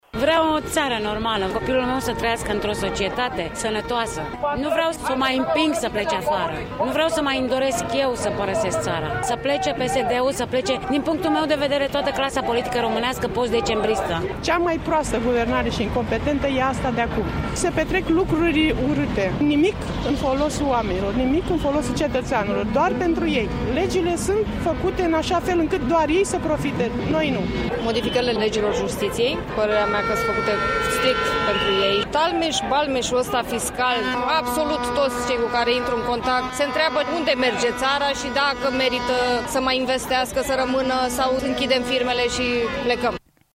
5-nov-ora-20-vox-proteste-bucuresti.mp3